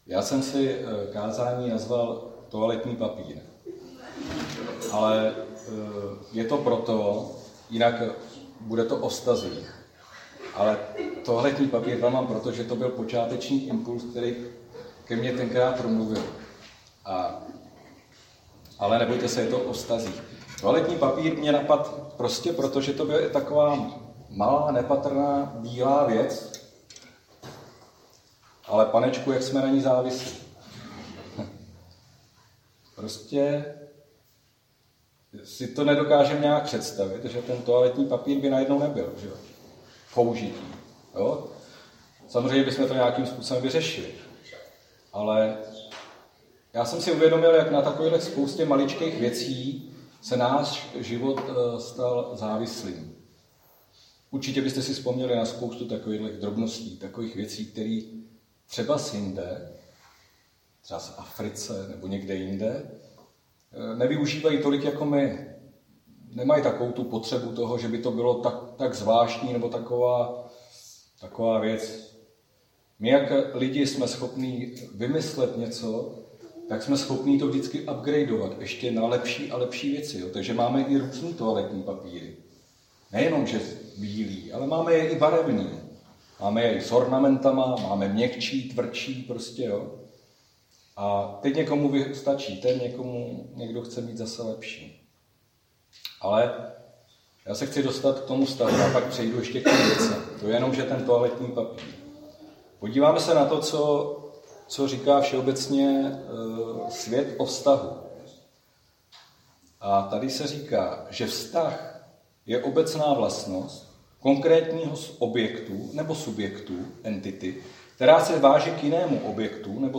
Křesťanské společenství Jičín - Kázání 8.8.2021